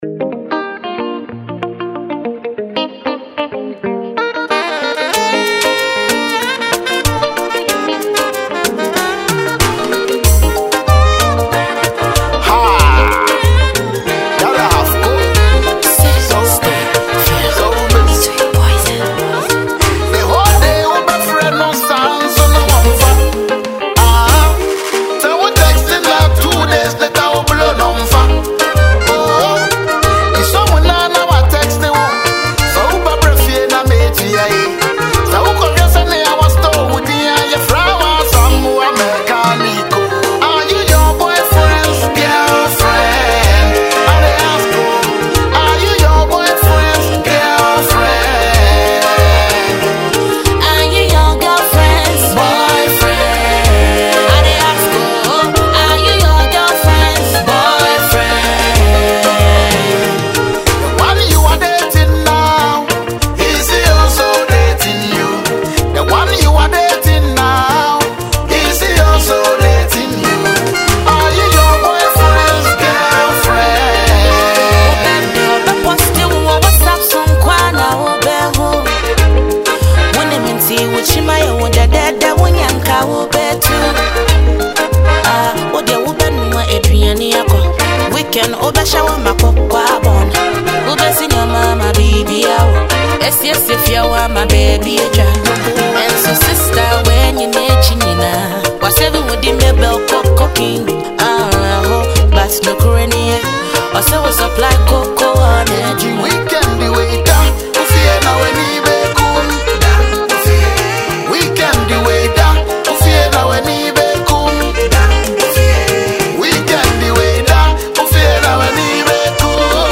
Highlife
remix song